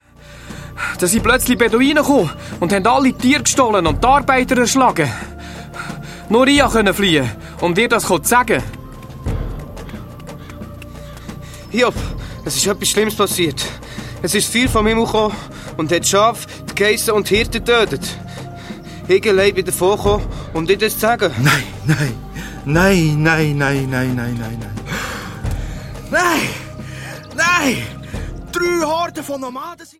Hörspiel-Album